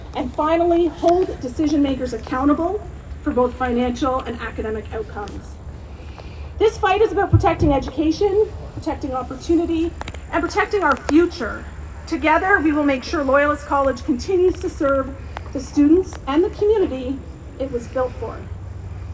With chants of ‘our college, our future,’ a large number of faculty, support staff, students and union supporters rallied at Loyalist College Wednesday afternoon.